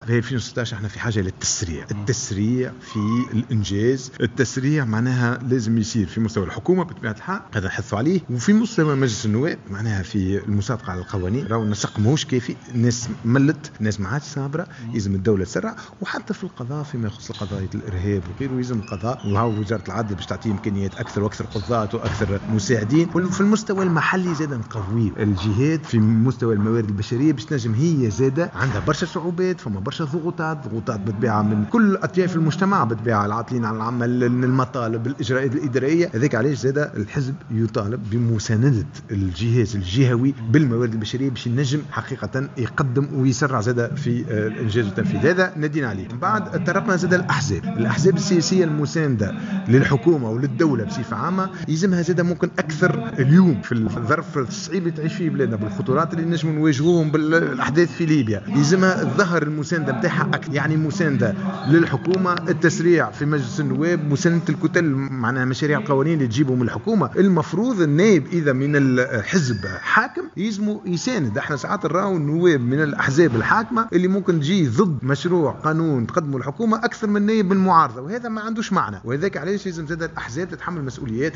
وقال إنه يُفترض أن يساند النواب من أحزاب الائتلاف الحاكم مشاريع قوانين الحكومة وعدم معارضتها، بحسب تعبيره في تصريحات صحفية على هامش المؤتمر الوطني لحزب آفاق تونس.